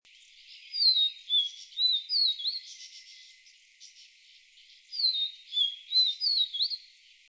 59-3玉山2012黃腹琉璃4-1.mp3
黃腹琉璃 Niltava vivida vivida
南投縣 信義鄉 玉山塔塔加
錄音環境 森林
行為描述 鳴唱
Sennheiser 型號 ME 67